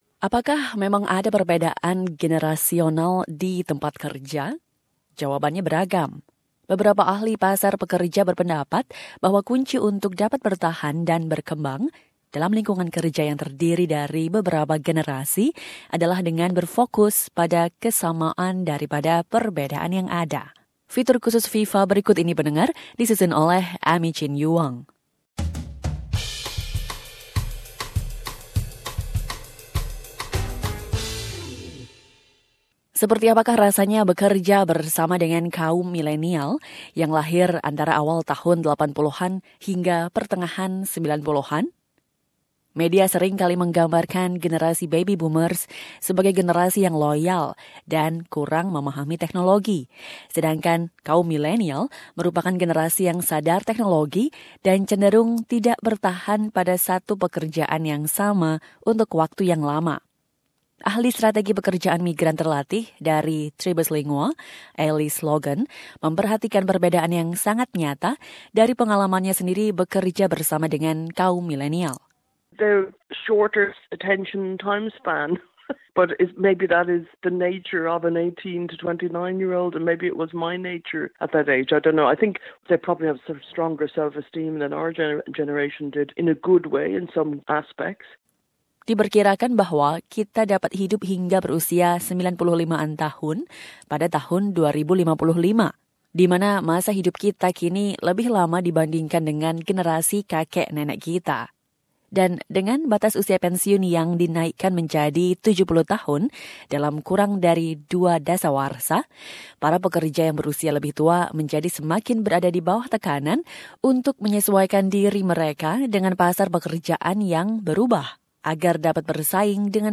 Dalam Viva episode kali ini, SBS berbicara dengan beberapa pakar pasar pekerja dan seorang peneliti populasi menua, yang berpendapat bahwa kunci untuk dapat bertahan dan berkembang dalam lingkungan yang multigenerasional adalah dengan berfokus pada kesamaan yang ada, bukan pada perbedaan.